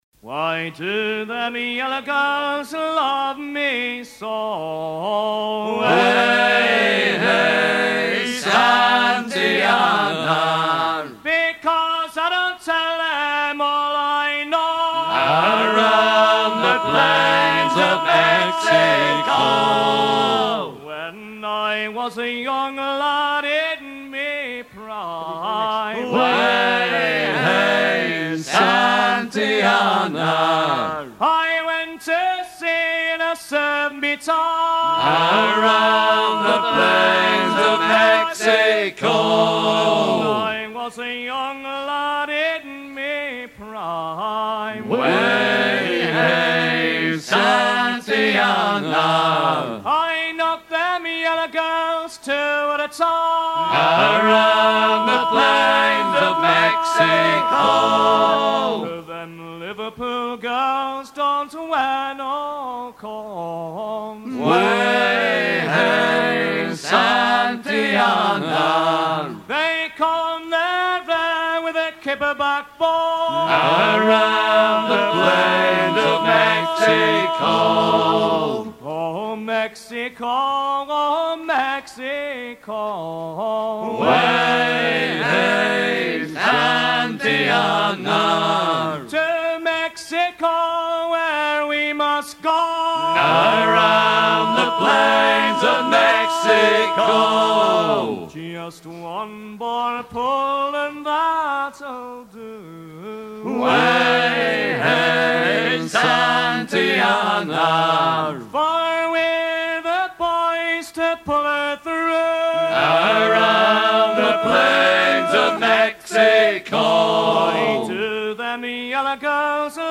chants de travail américains appréciés des matelots anglophones
Pièce musicale éditée